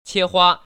切花[qiēhuā]